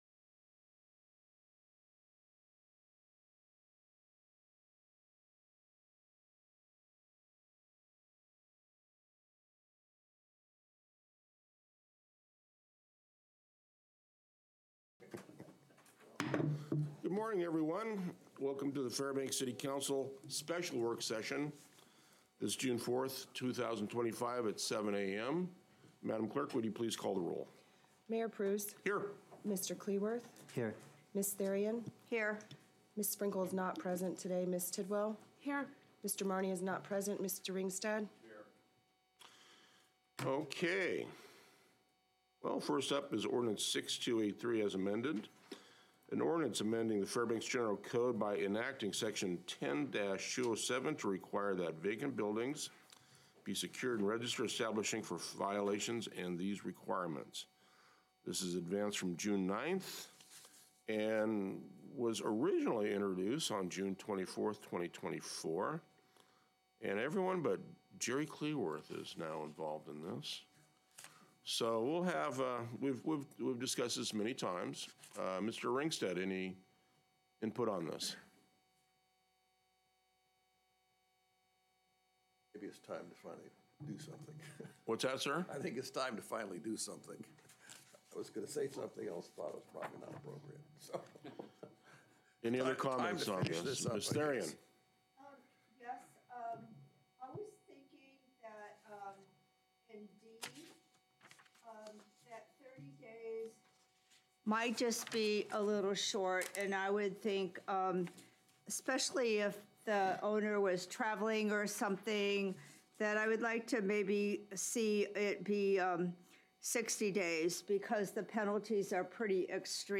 Special City Council Work Session